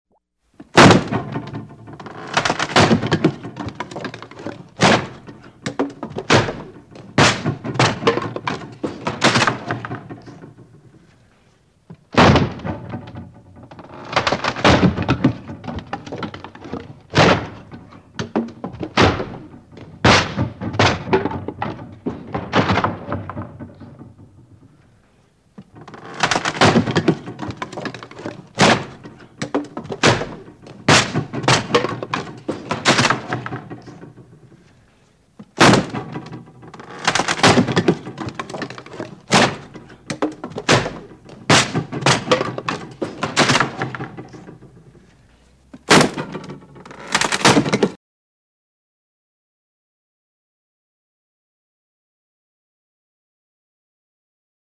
Звуки разрушений в строительных работах
Ломают деревянную конструкцию
razbivajut-derevjannuju-konstrukciju.mp3